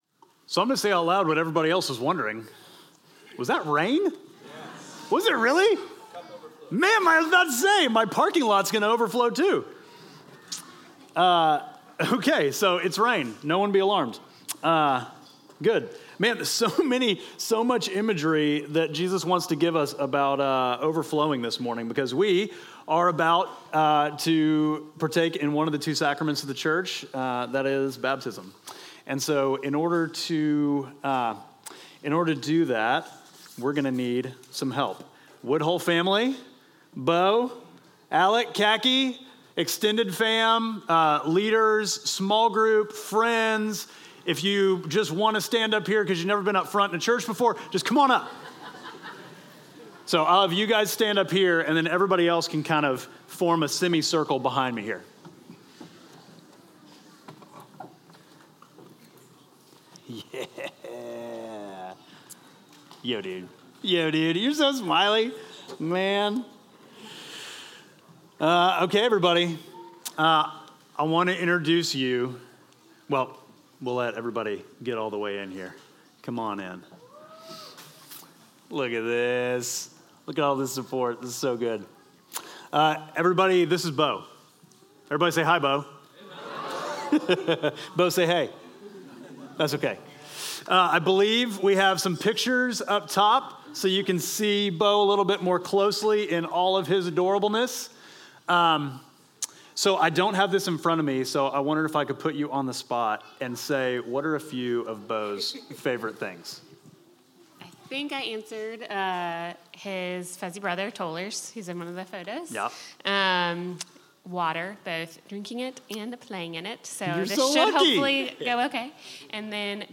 Midtown Fellowship Crieve Hall Sermons Overflowing to Others Jul 28 2024 | 00:41:55 1x 00:00 / 00:41:55 Subscribe Share Apple Podcasts Spotify Overcast RSS Feed Share Link Embed